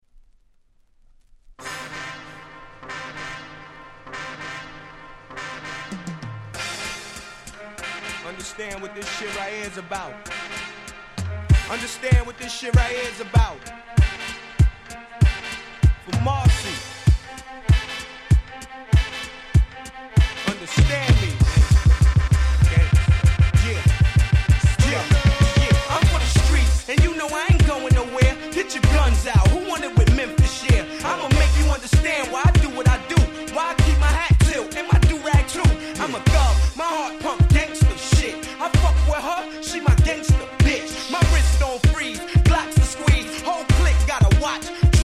00' Super Hit Hip Hop !!
今聞いても血がたぎる！！